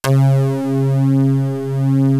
JD SYNTHD1.wav